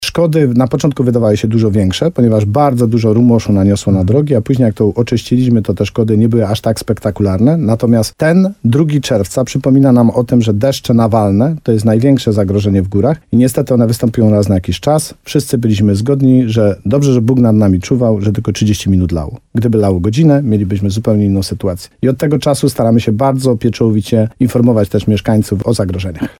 Od tego czasu staramy się bardzo pieczołowicie informować mieszkańców o zagrożeniach – mówił radiu RDN Nowy Sącz wójt gminy Ochotnica Dolna Tadeusz Królczyk.